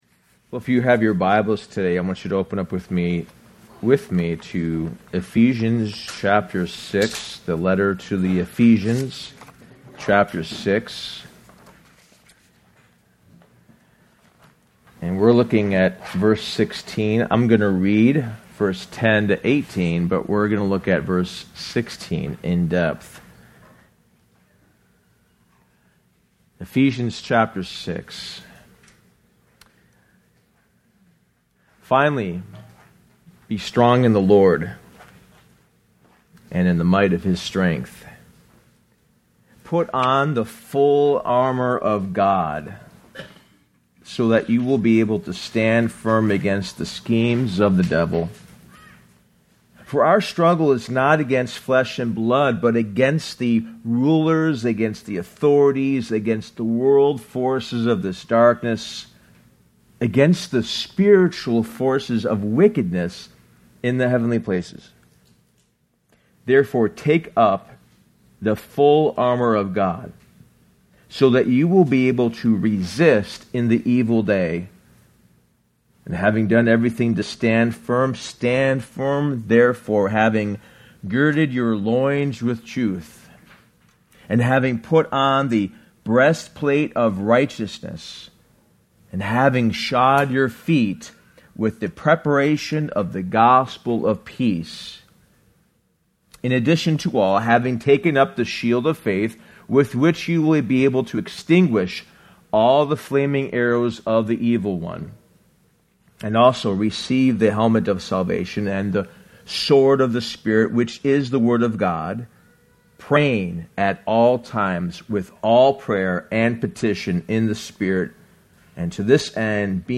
Sermon: Spiritual Warfare#14: Take Up Your Shield